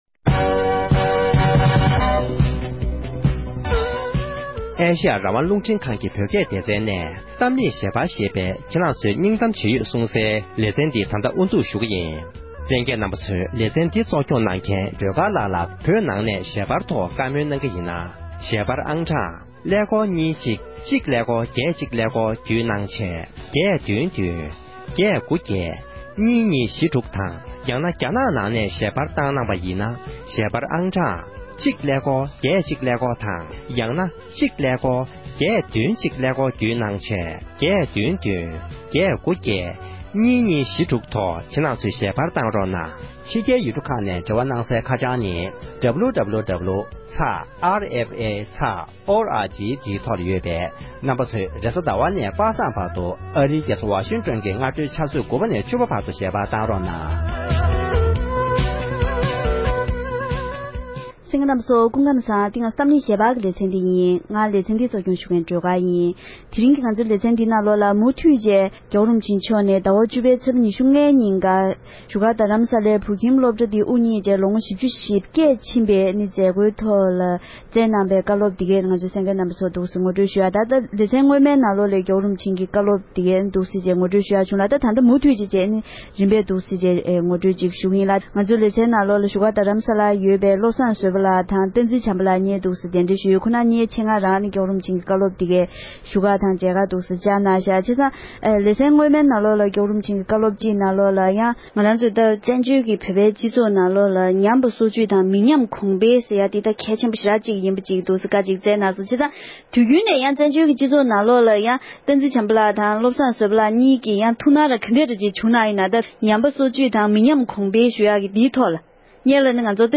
རྡ་ས་བོད་ཁྱིམ་དབུ་བརྙེས་ནས་མི་ལོ་ཞེ་བརྒྱད་འཁོར་བའི་མཛད་སྒོའི་སྐབས་༸གོང་ས་མཆོག་ནས་བསྩལ་བའི་གསུང་འཕྲིན།